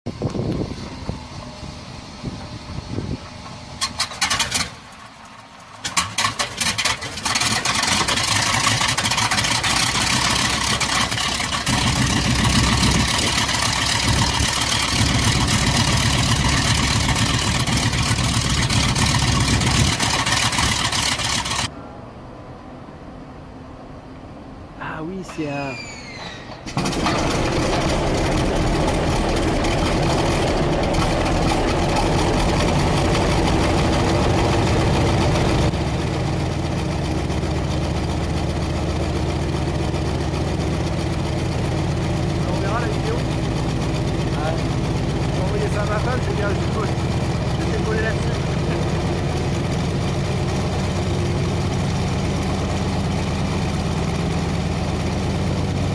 Big Old RADIAL AIRPLANE ENGINES